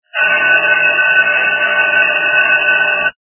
При прослушивании Звонок - Школьный звонок качество понижено и присутствуют гудки.
Звук Звонок - Школьный звонок